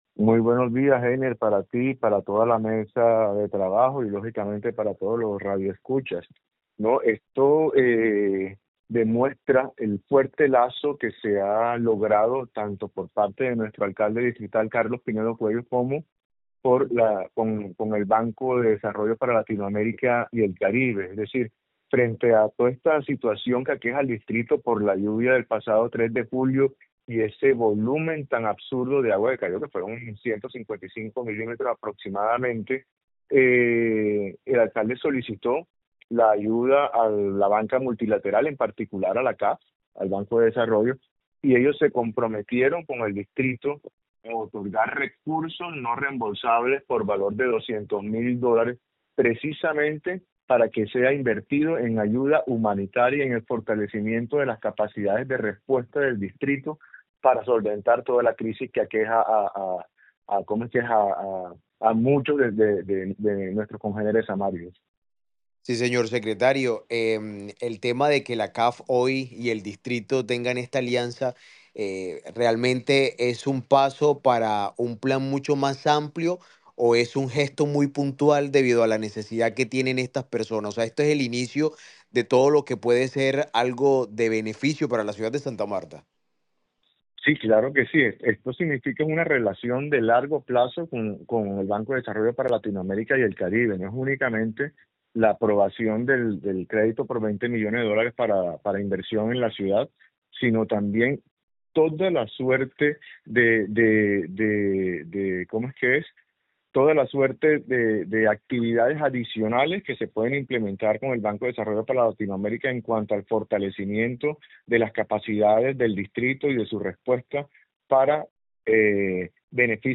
SECRETARIO DE HACIENDA, GONZALO GUTIÉRREZ